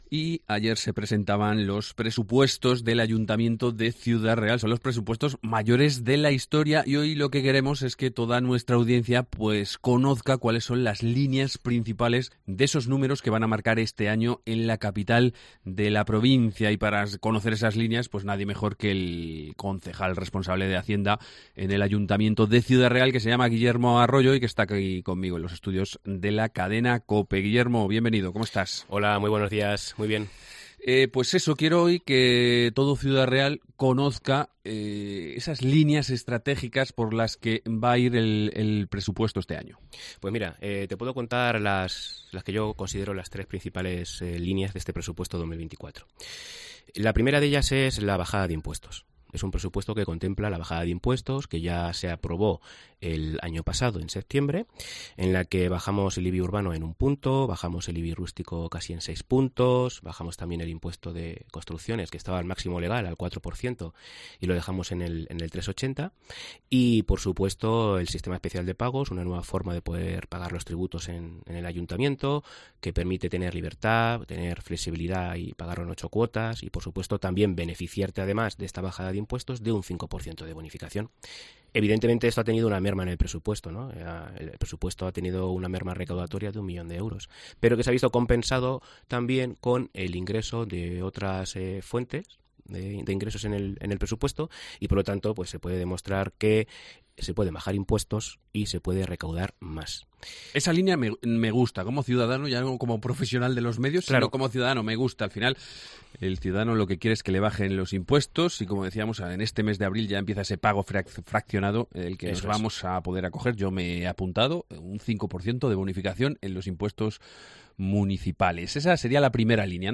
Entrevista con el concejal de Hacienda, Guillermo Arroyo